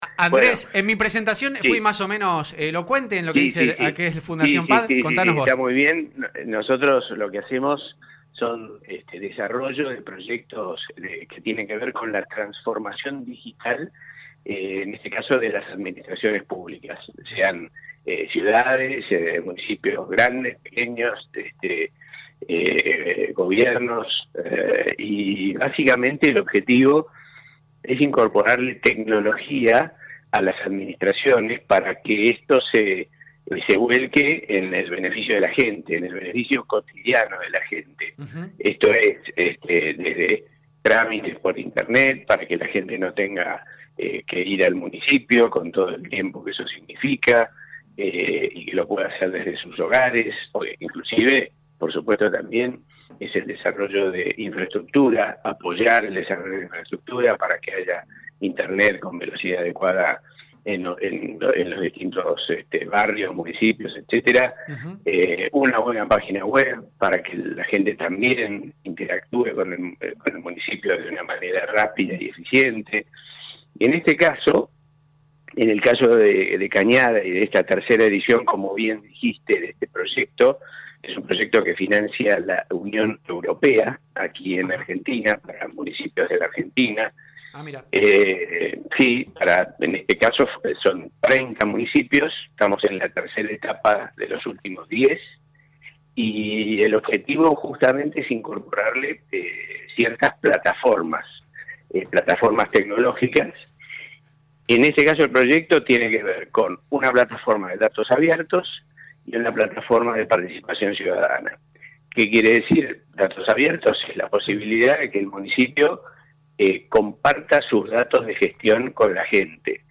Escuchá la nota completa de Un Buen Día con Andrés Ibarra